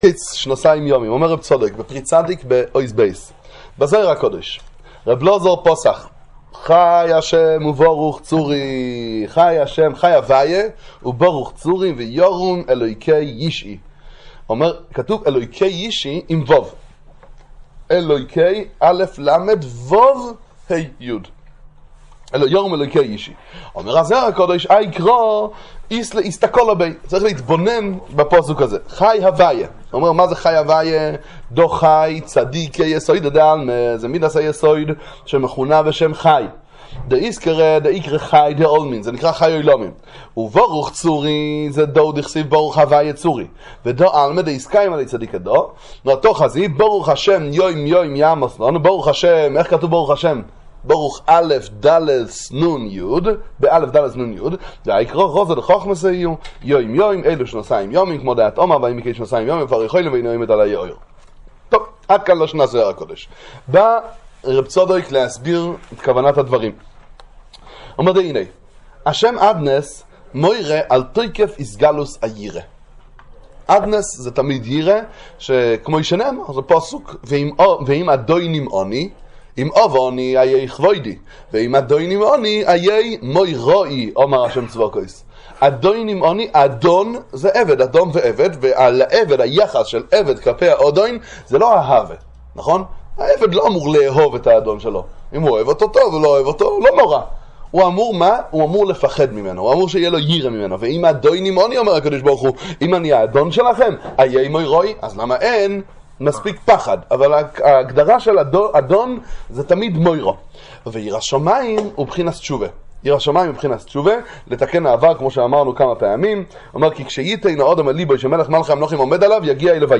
שיעורי תורה לצפיה על פרשת השבוע, ספרי חסידות